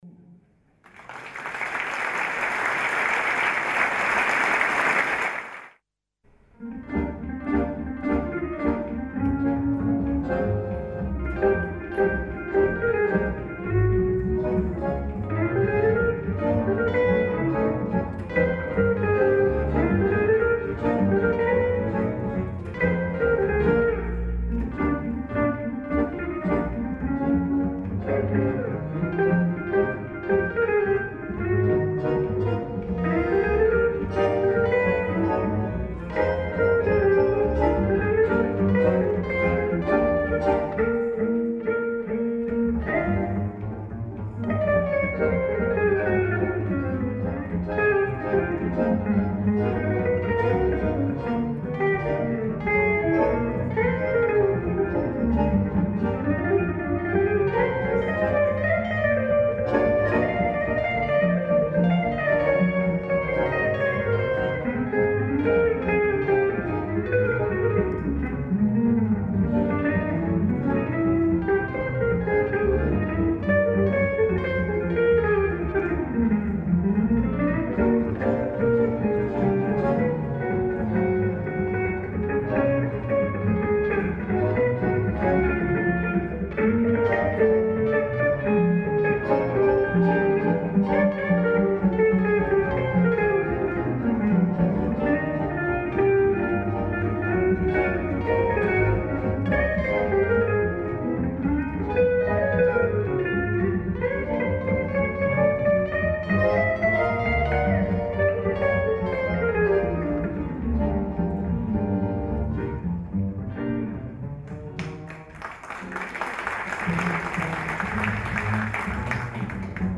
accordéon
guitare
contrebasse